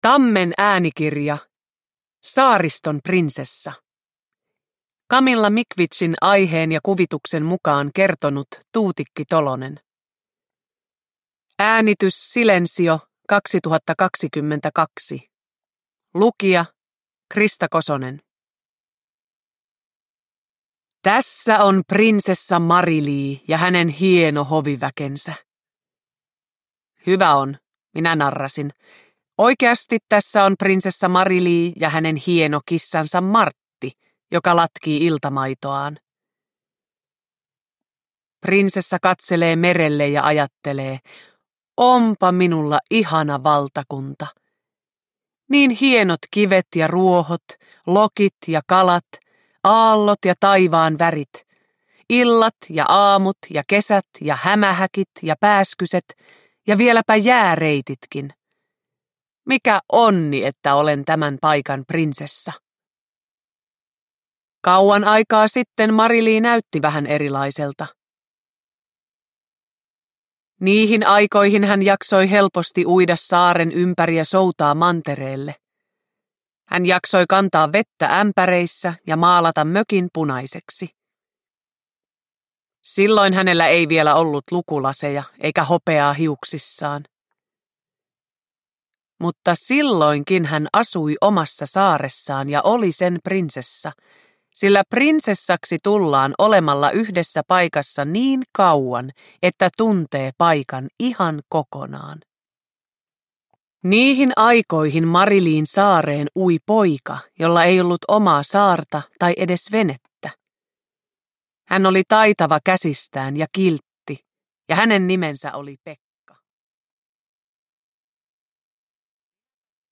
Saariston prinsessa – Ljudbok – Laddas ner